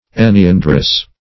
Search Result for " enneandrous" : The Collaborative International Dictionary of English v.0.48: Enneandrian \En`ne*an"dri*an\, Enneandrous \En`ne*an"drous\, a. (Bot.) Having nine stamens.